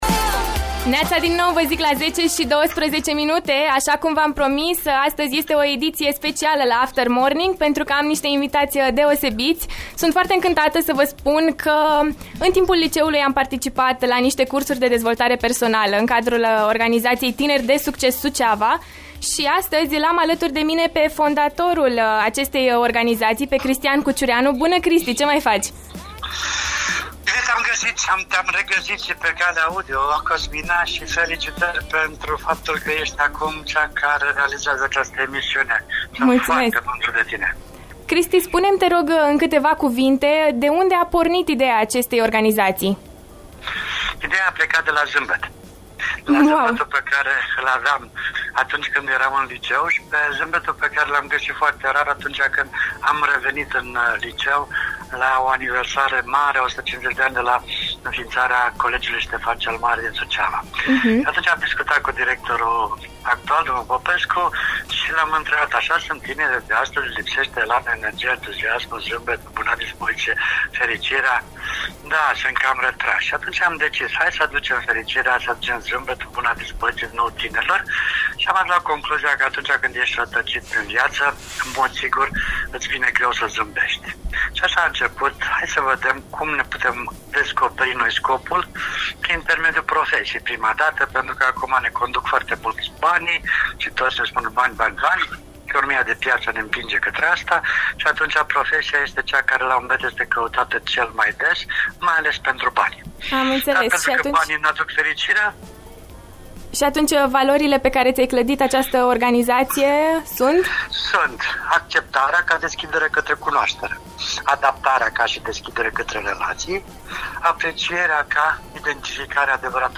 Astăzi, la After Morning, ne-am bucurat de prezența membrilor organizației TINERI DE